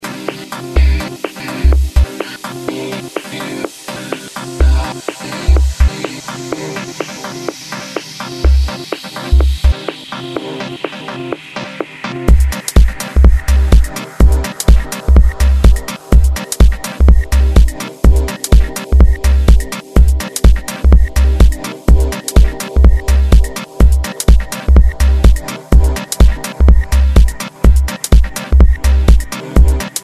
presents a deep and dubby Techno Tune with his own vocals.
Kompakt/Ware styled version